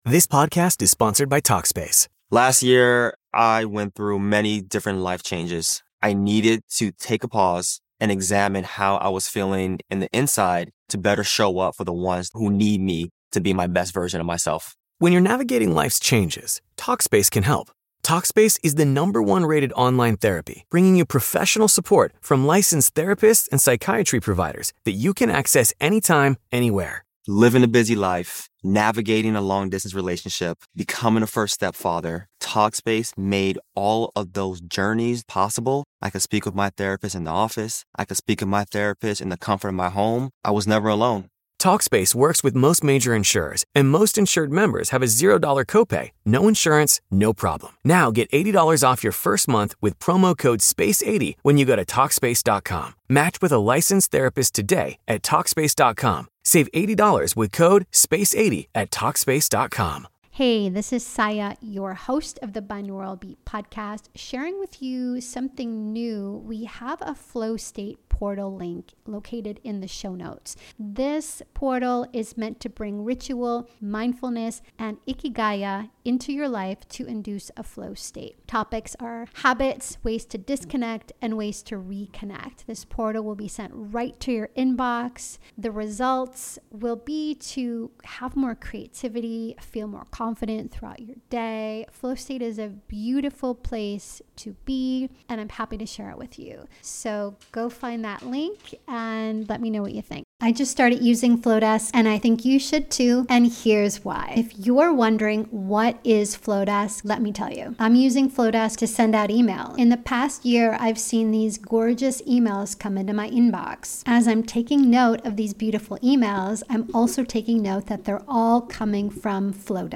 Mindfulness and sound healing — woven into every frequency.